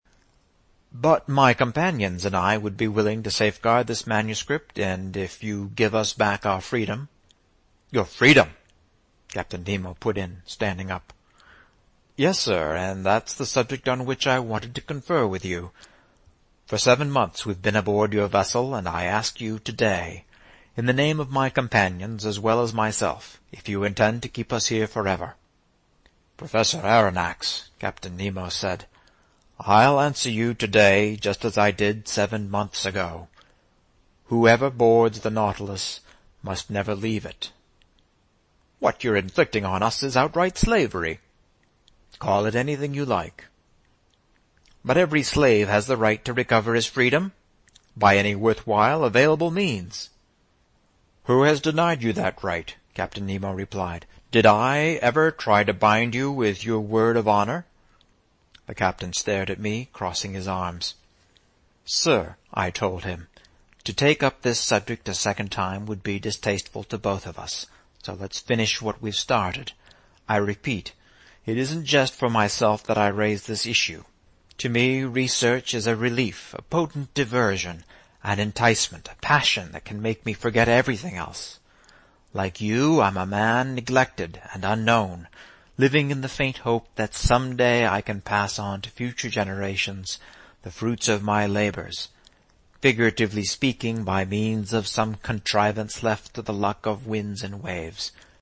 在线英语听力室英语听书《海底两万里》第521期 第32章 海湾暖流(11)的听力文件下载,《海底两万里》中英双语有声读物附MP3下载